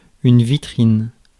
Ääntäminen
US : IPA : /ˈʃoʊ.ˌkeɪs/ UK : IPA : /ˈʃəʊˌkeɪs/